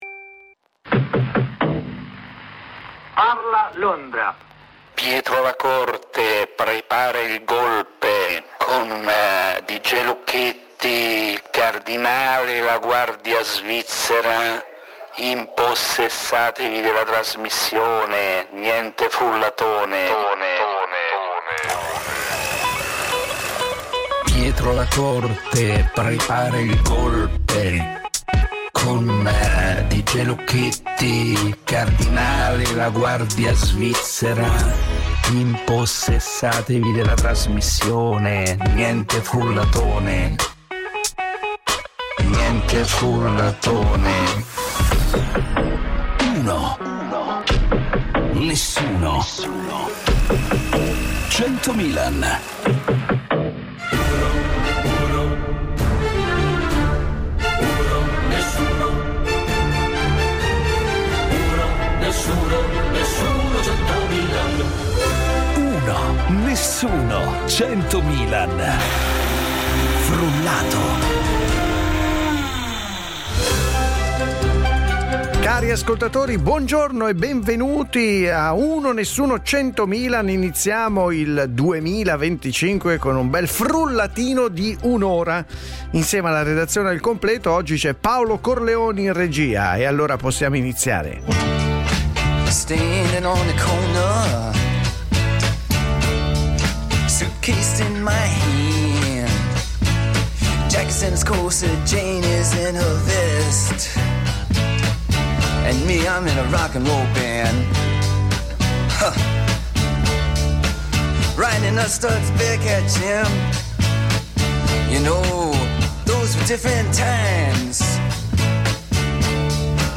Un morning show sui fatti e i temi dell’attualità